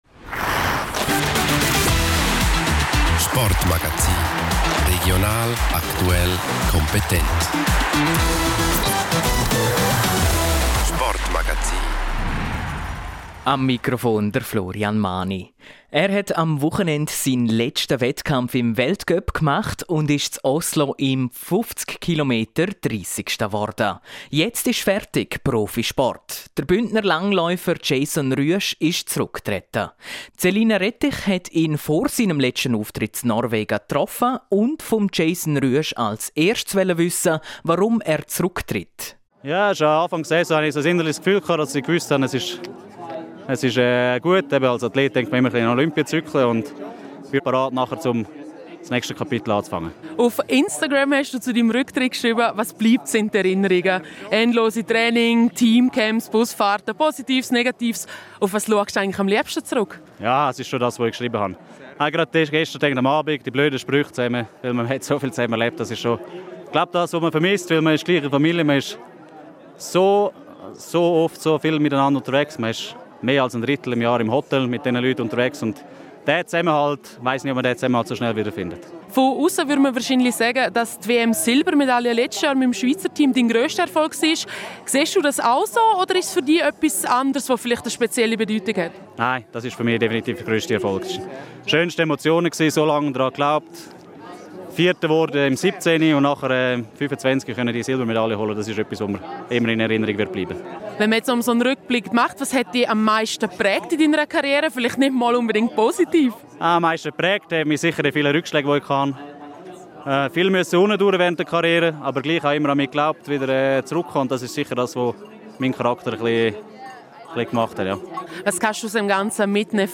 • Meldungen zu Sportlerinnen und Sportlern sowie Teams aus dem Sendegebiet.